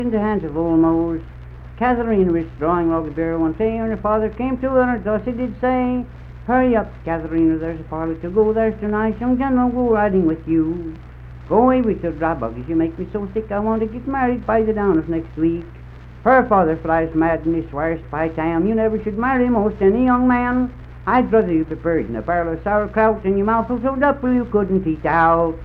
Unaccompanied vocal music
Verse-refrain 4(4
Voice (sung)